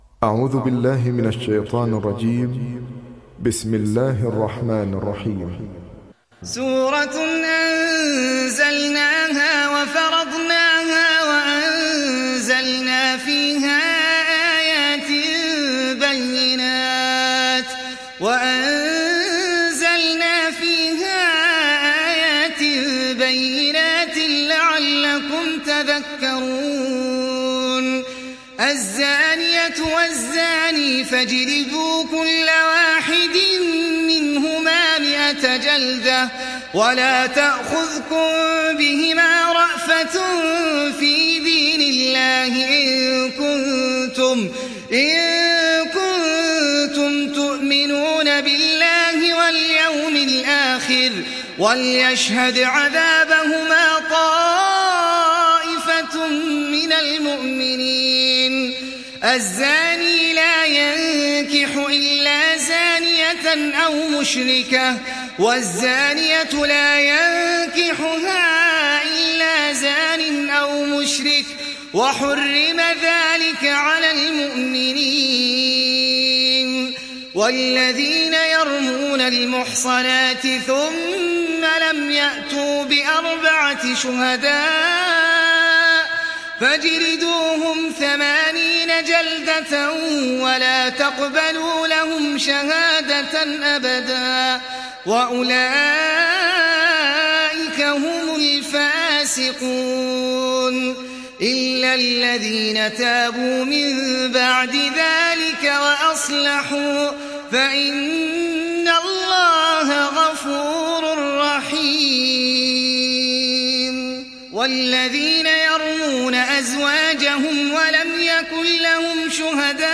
تحميل سورة النور mp3 بصوت أحمد العجمي برواية حفص عن عاصم, تحميل استماع القرآن الكريم على الجوال mp3 كاملا بروابط مباشرة وسريعة